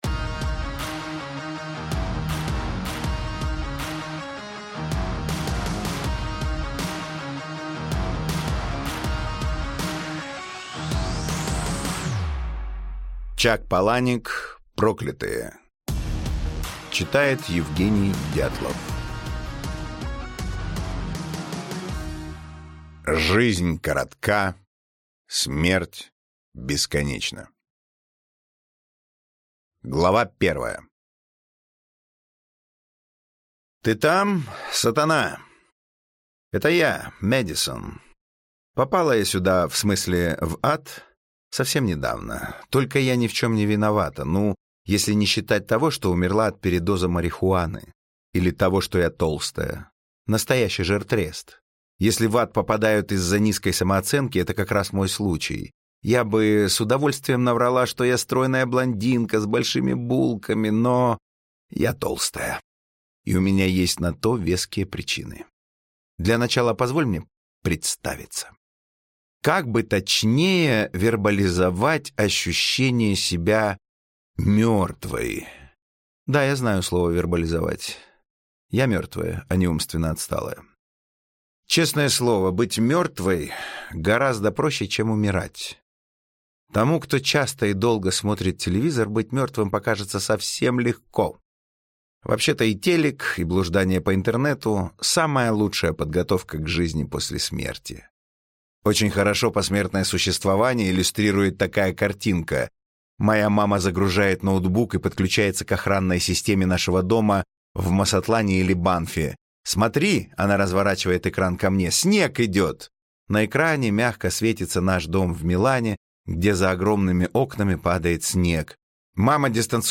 Аудиокнига Проклятые | Библиотека аудиокниг